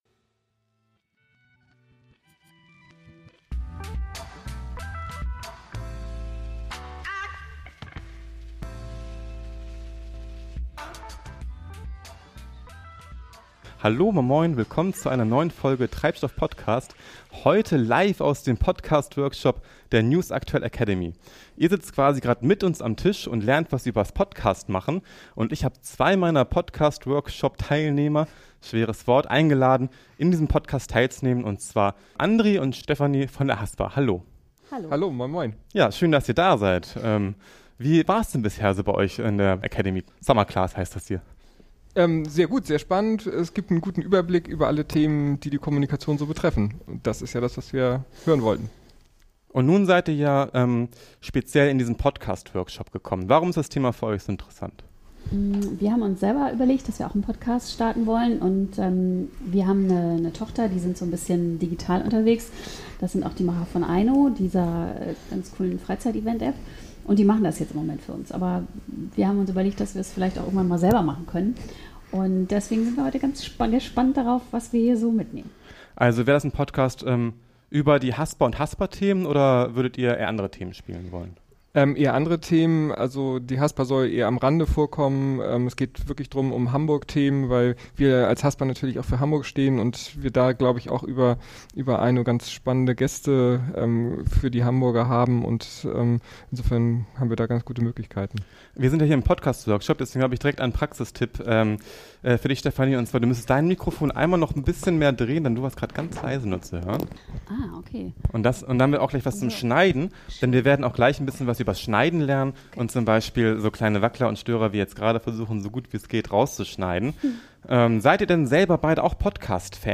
43: Podcast LIVE aus dem Workshop